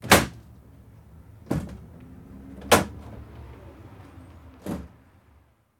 ambdooropen.ogg